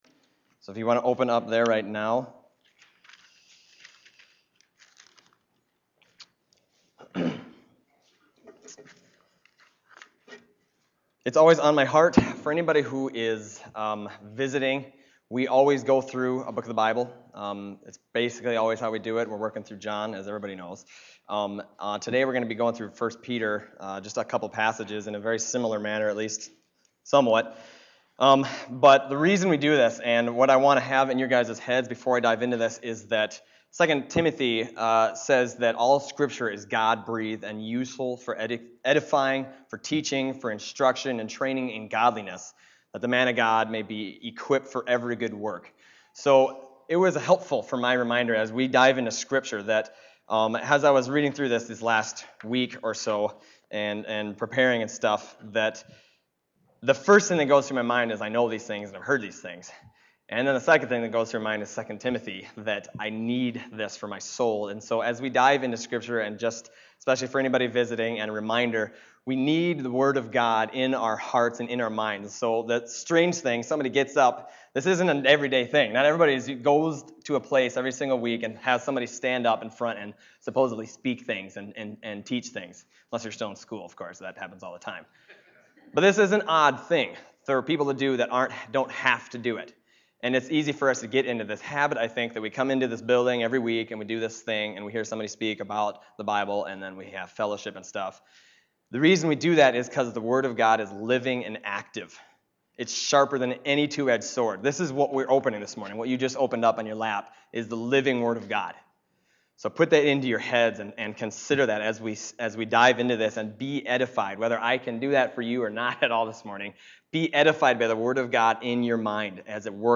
Other Passage: 1 Peter 4:7-11 Service Type: Sunday Morning 1 Peter 4:7-11 « Power and Prayer